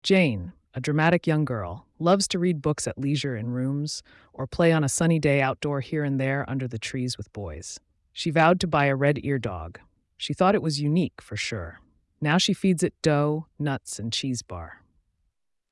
phonetic-pangram-alloy.mp3